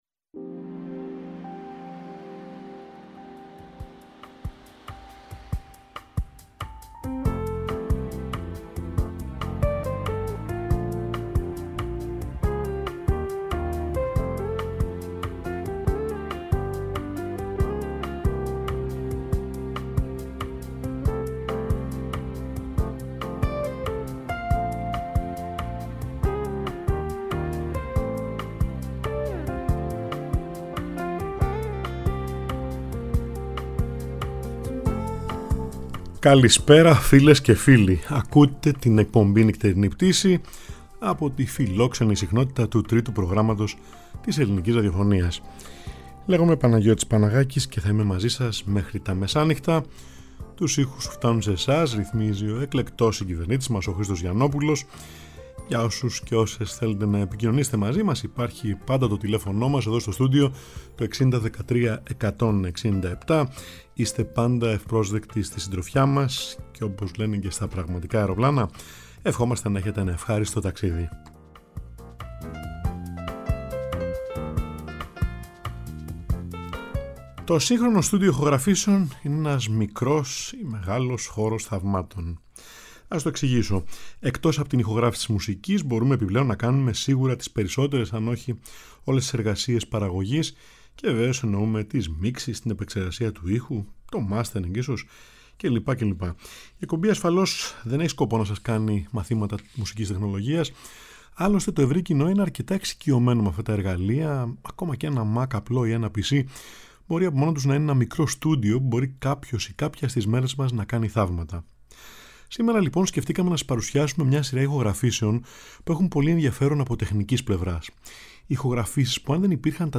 ακούγεται σε δυο πιάνα ταυτόχρονα